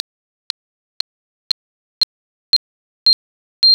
3. Tonos agudos: aquellos cuyas frecuencias van de 2 Khz. a 20 Khz. (frecuencias altas).
Ejemplo 3. Tono agudo.